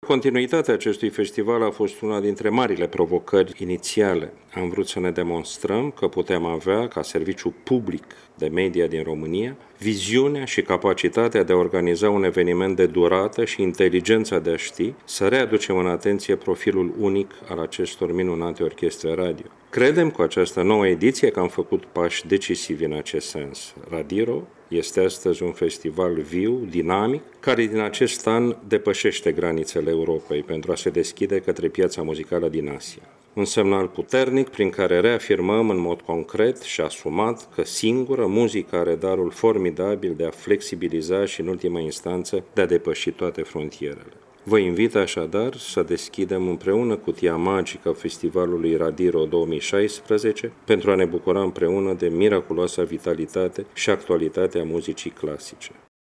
Preşedintele Director General al Radio România, Ovidiu Miculescu, ne-a vorbit despre ediţia din acest an a Festivalului Internaţional al Orchestrelor Radio: